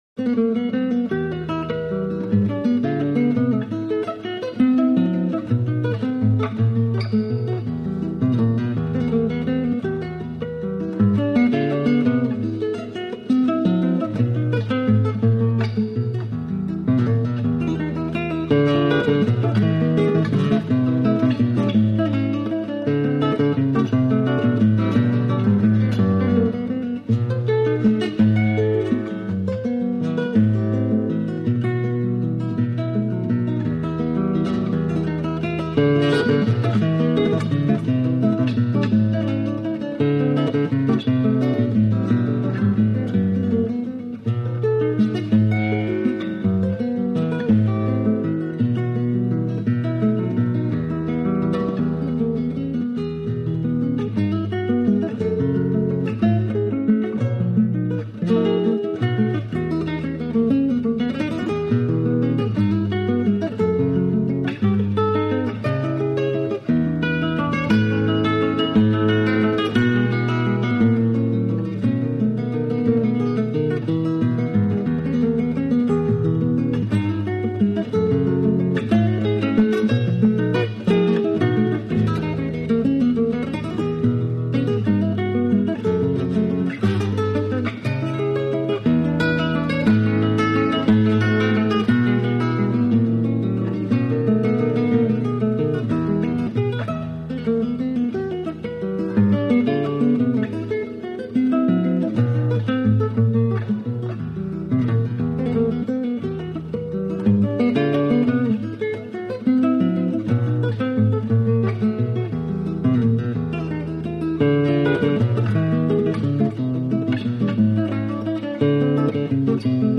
La música de América Latina.